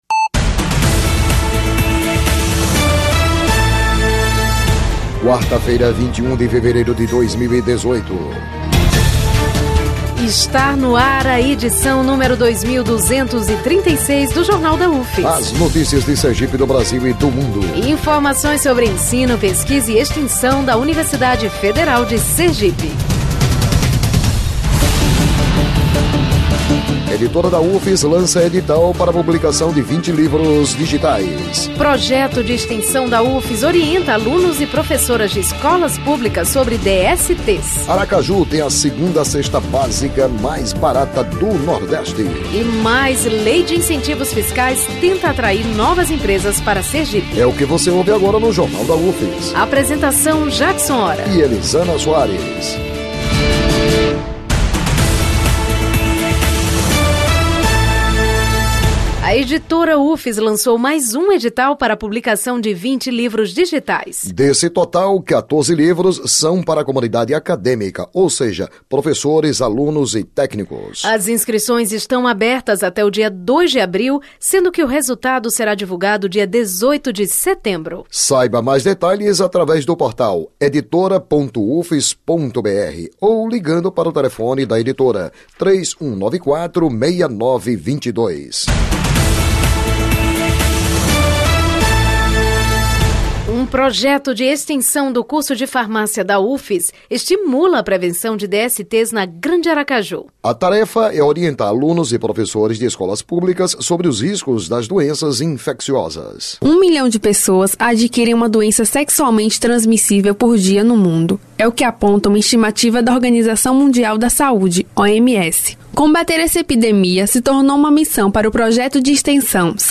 O Jornal da UFS desta quarta-feira, 21, apresenta um projeto de extensão do Departamento de Farmácia da UFS que realiza ações de prevenção e combate as infecções sexualmente transmissíveias (IST) e gravidez precoce em escolas públicas da Grande Aracaju. O noticiário vai ao ar às 11h na Rádio UFS, com reprises às 17h e 22h.